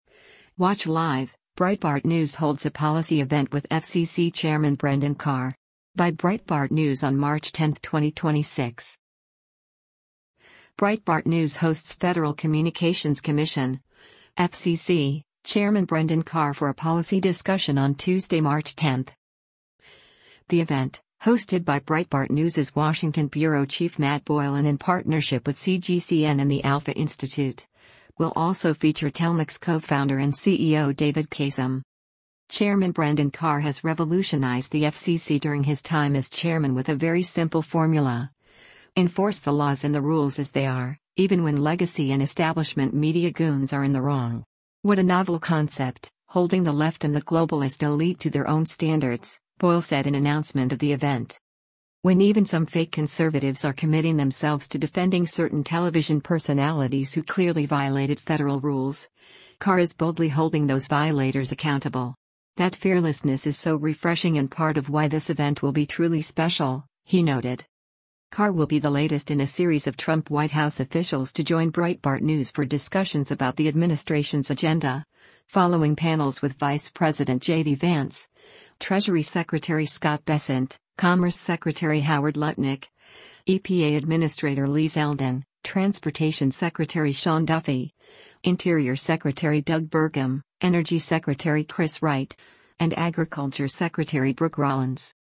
Breitbart News hosts Federal Communications Commission (FCC) Chairman Brendan Carr for a policy discussion on Tuesday, March 10.